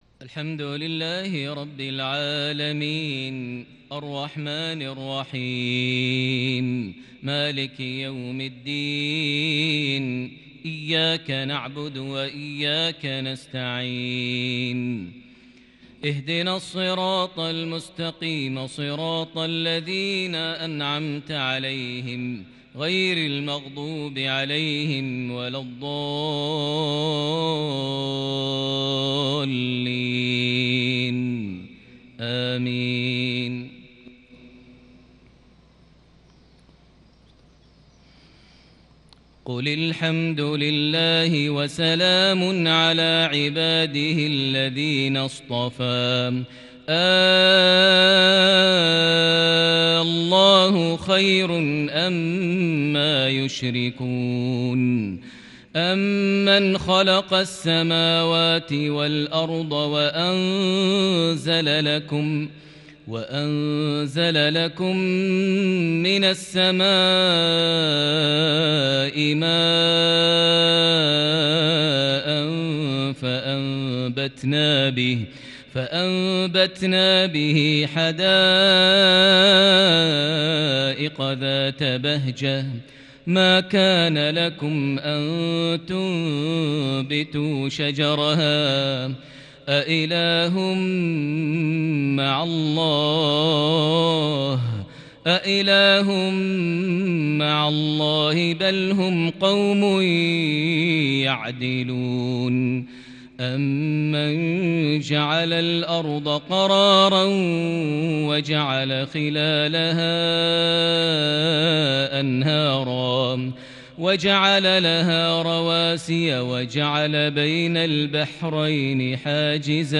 (أإله مع الله ) دلائل وحدانية الله بأداء كردي خيالي | مغرب السبت 25 ذو الحجة 1441هـ (59-66) > 1441 هـ > الفروض - تلاوات ماهر المعيقلي